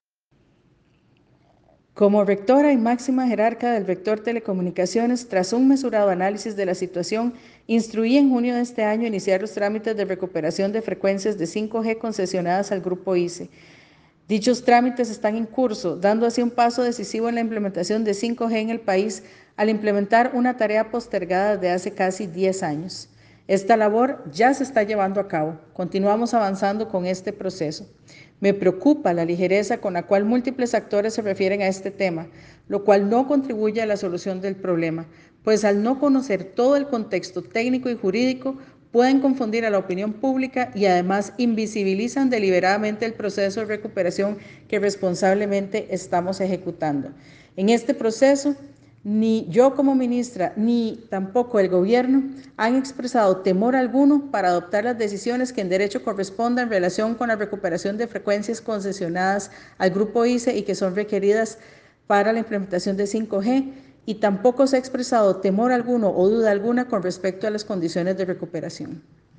Declaraciones de la ministra Paola Vega Castillo recuperación de frecuencias concesionadas al Grupo ICE